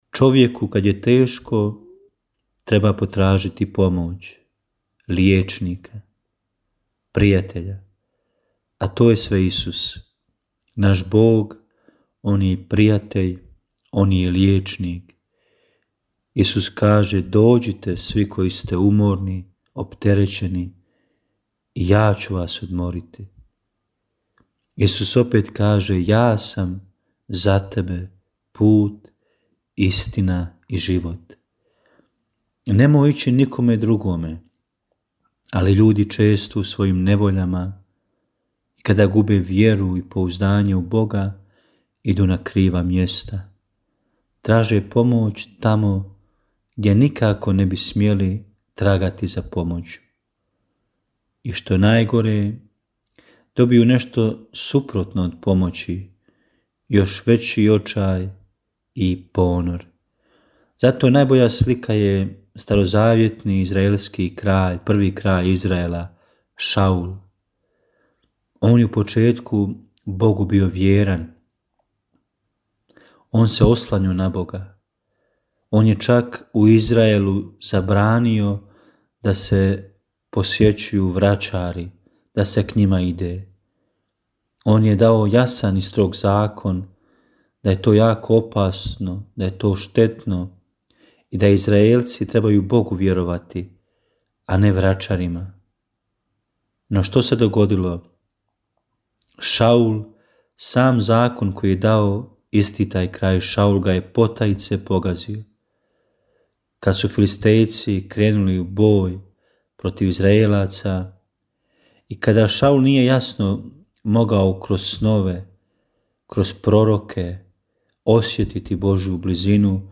Kratku emisiju ‘Duhovni poticaj – Živo vrelo’ slušatelji Radiopostaje Mir Međugorje mogu čuti od ponedjeljka do subote u 3 sata i u 7:10. Emisije priređuju svećenici i časne sestre u tjednim ciklusima.